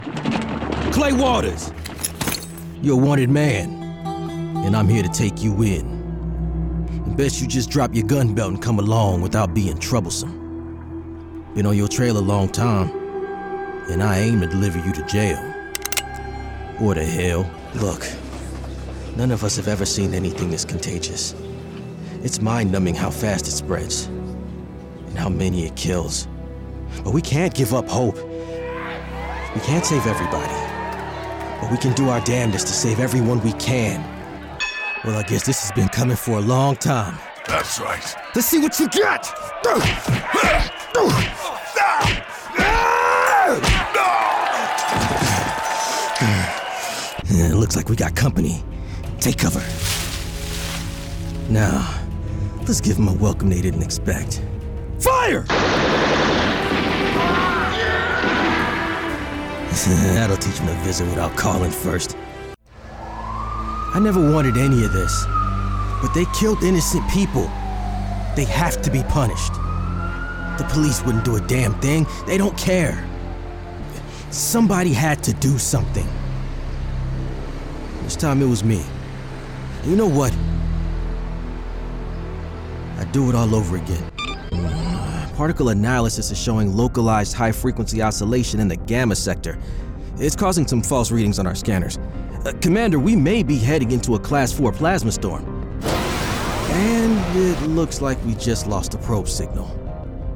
Comercial, Urbana, Cool, Amable, Cálida, Natural
If you’re looking for a grounded, believable male voice for your project, look no further!